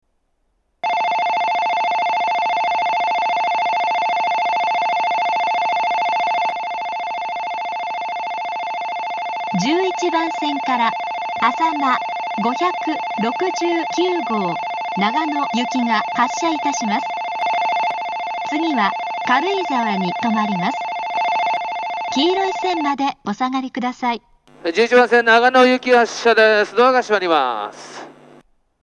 在来線では全ホーム同じ発車メロディーが流れますが、新幹線ホームは全ホーム同じ音色のベルが流れます。
１１番線発車ベル 主に長野新幹線が使用するホームです。
あさま５６９号長野行の放送です。